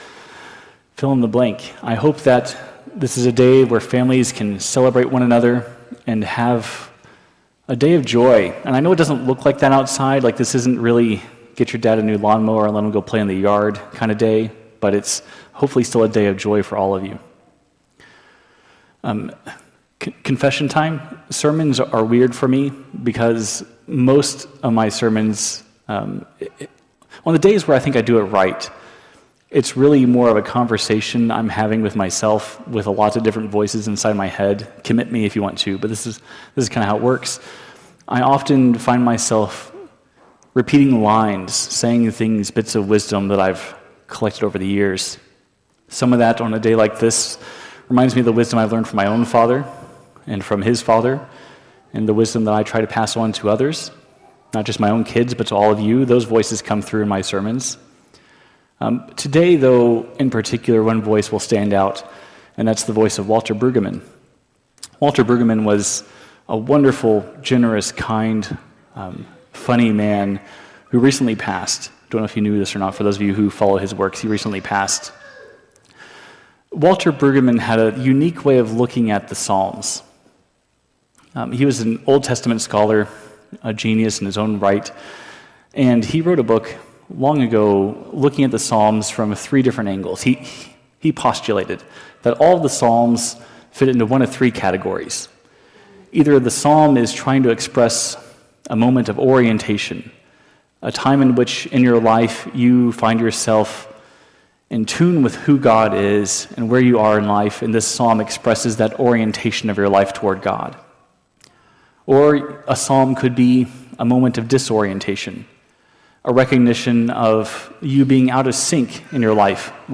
The sermon encourages worship that opens our hearts to the mystery and love of God active among us.